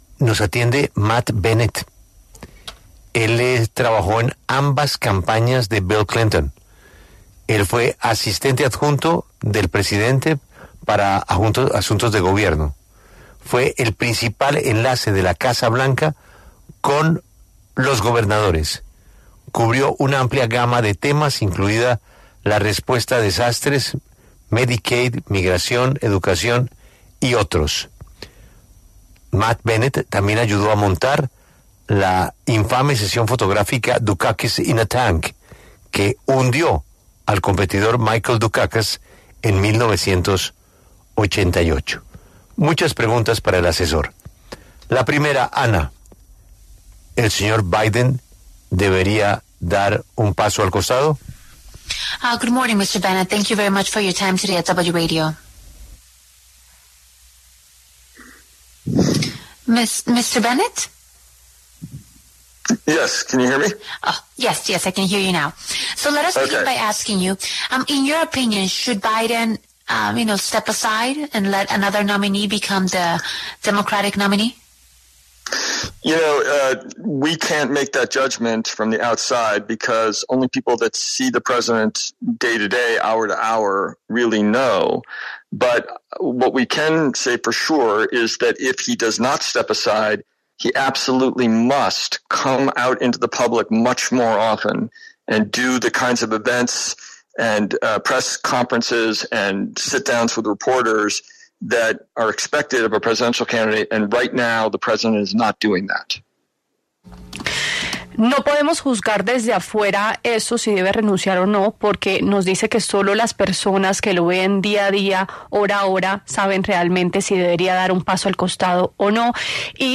conversó con La W sobre el fallido debate de Joe Biden y las elecciones presidenciales de EE.UU.